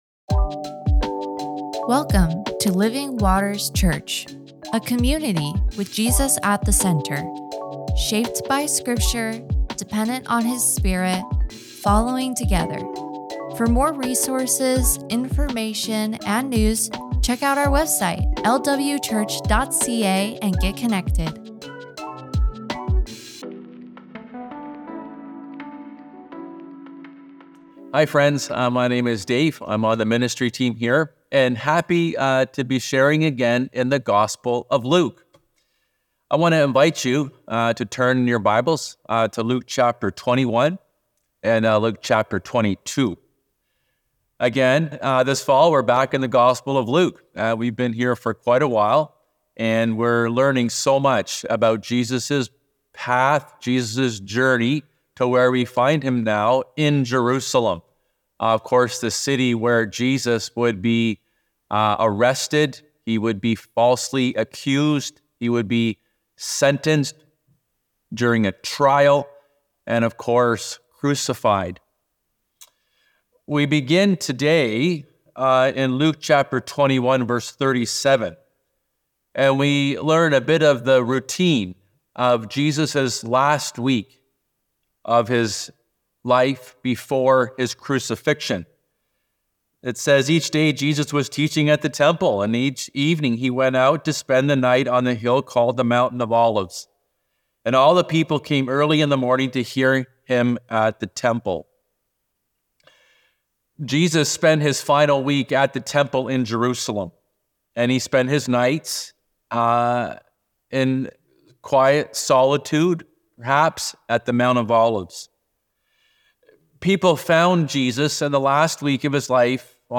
Podcasts | Living Waters Church